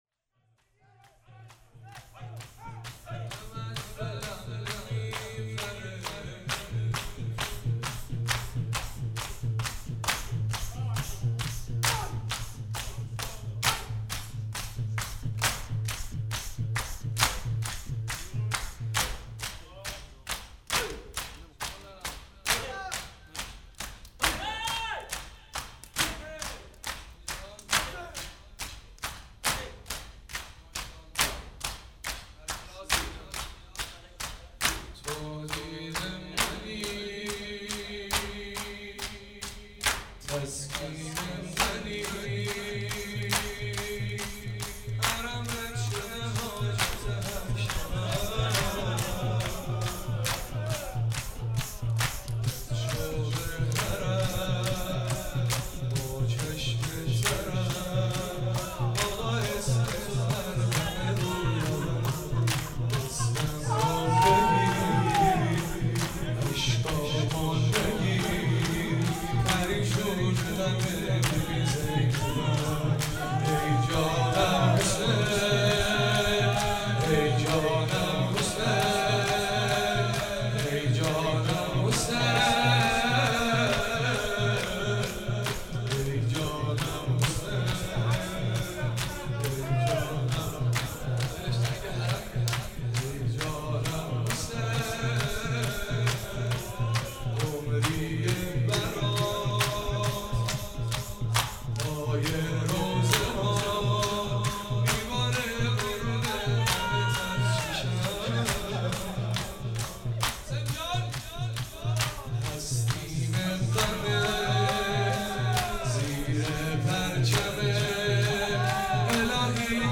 چهاراه شهید شیرودی حسینیه حضرت زینب (سلام الله علیها)
شور